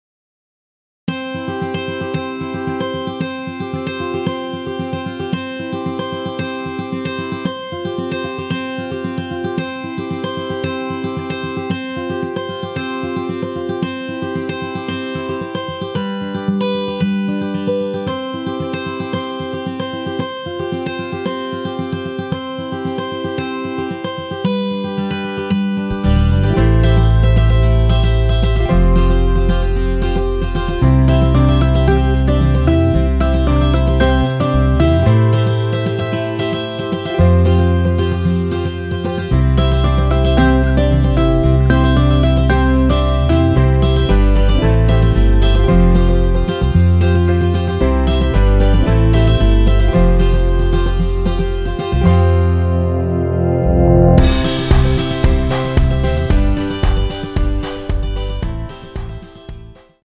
여성분이 부르실 수 있는키의 MR입니다.(미리듣기 확인)
원키에서(+7)올린 MR입니다.
앞부분30초, 뒷부분30초씩 편집해서 올려 드리고 있습니다.